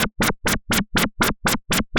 Index of /musicradar/rhythmic-inspiration-samples/120bpm
RI_RhythNoise_120-04.wav